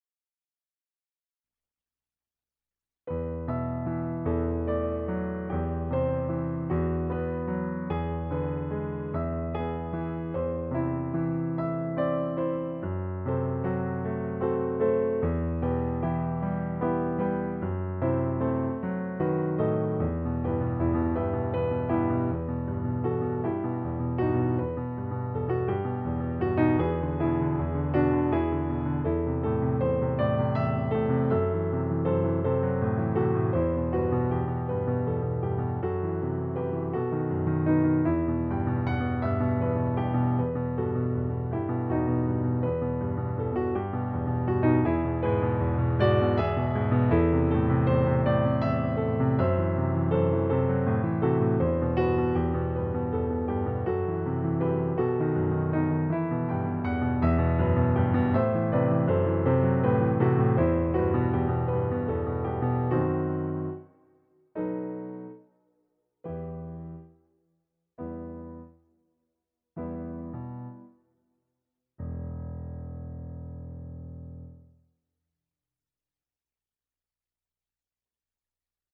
Segue nesse novo projeto, a gravação audiovisual dos arranjo para as 17 músicas do Cordão, para canto coral e piano.
Piano s/ Clique